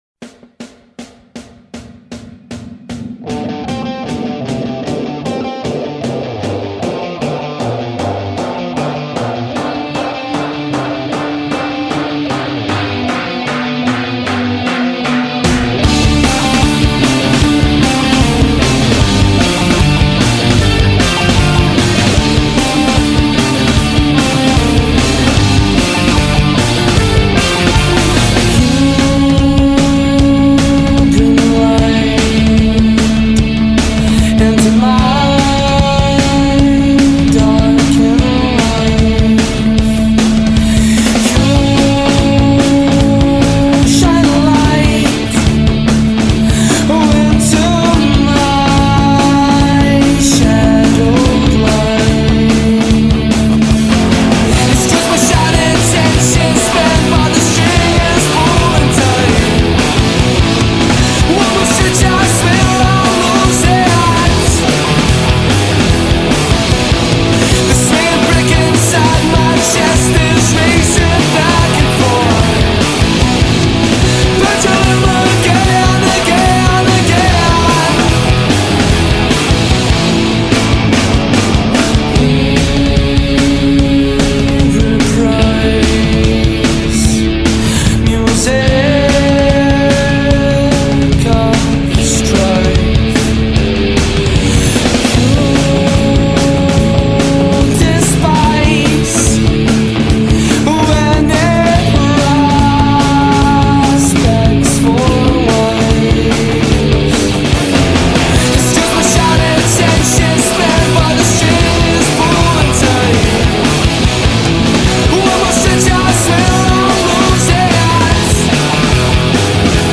Glasgow Scotland based five-piece